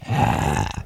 zombie-1.ogg